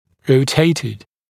[rəu’teɪtɪd][роу’тэйтид]ротированный, повернутый по оси